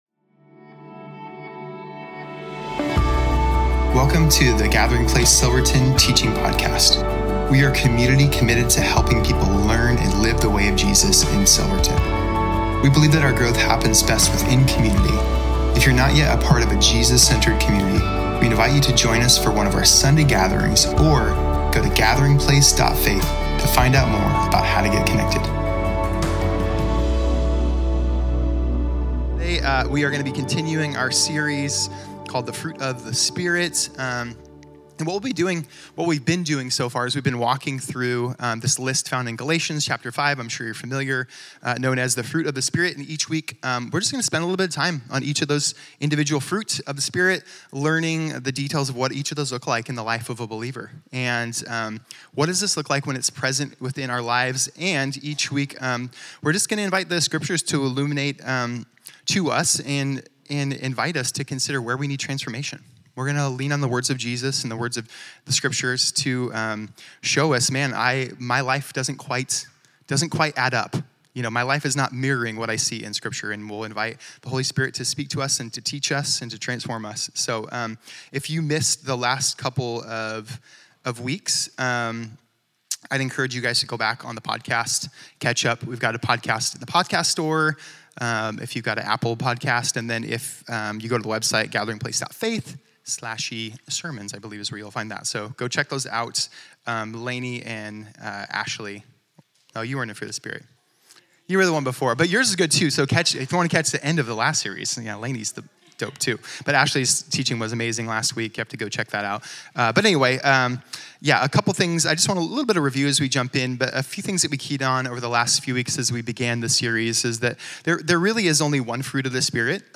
Home About Connect Events Sermons Give The Fruit of The Spirit-Part 3-Peace October 19, 2025